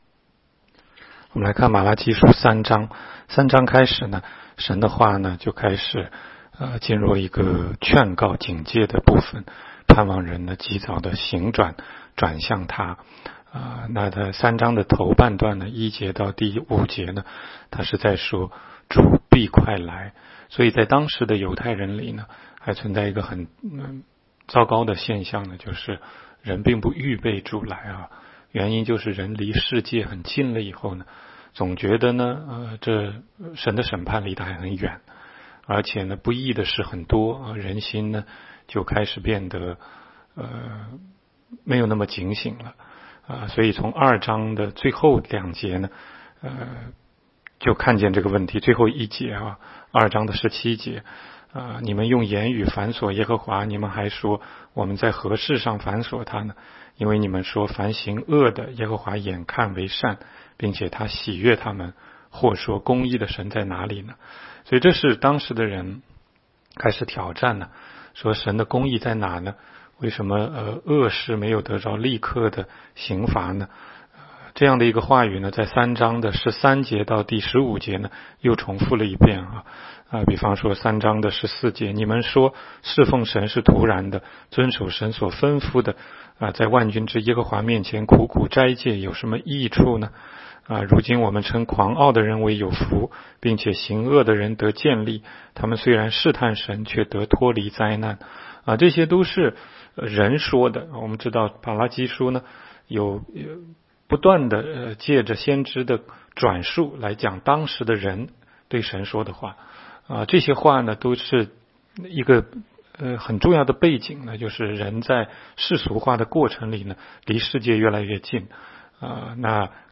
16街讲道录音 - 每日读经 -《玛拉基书》3章